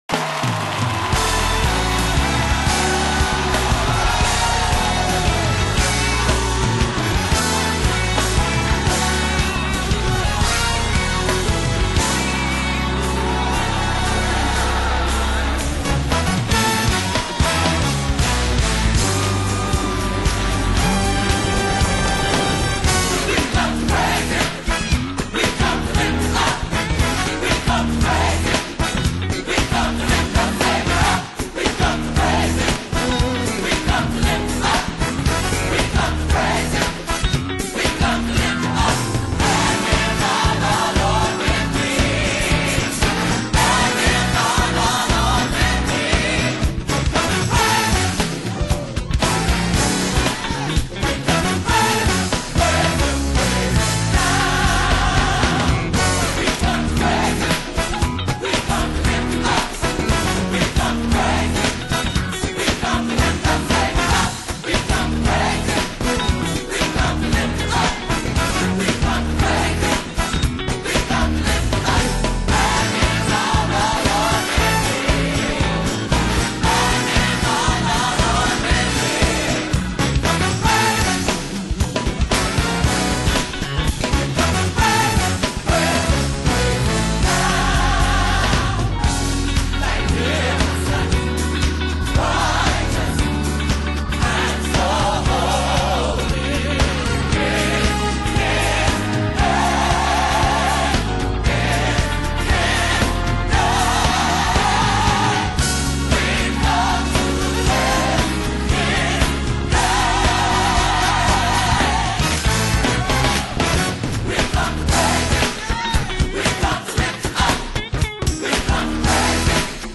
01WeComeToPraiseHimChoir.mp3